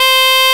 CHANTER C2.wav